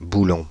Ääntäminen
Ääntäminen France (Île-de-France): IPA: /bu.lɔ̃/ Haettu sana löytyi näillä lähdekielillä: ranska Käännös Substantiivit 1. болт {m} Suku: m .